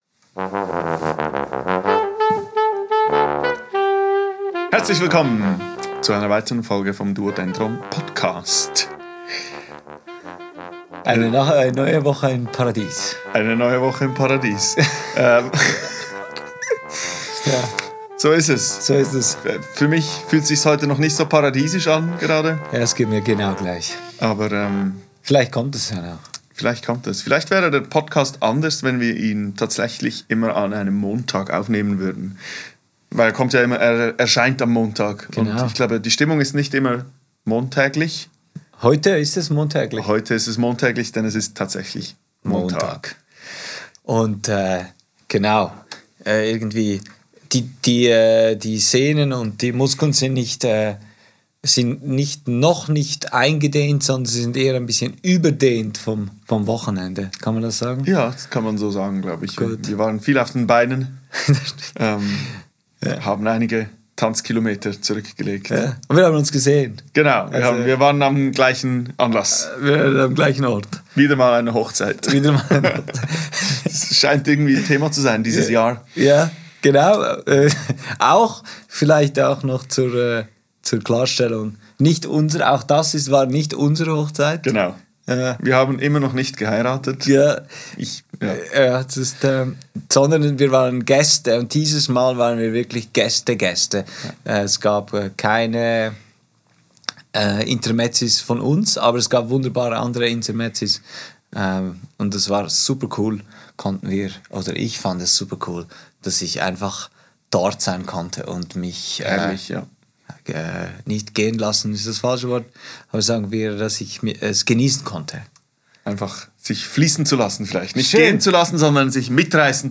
Aufgenommen am 24.06.2024 im Atelier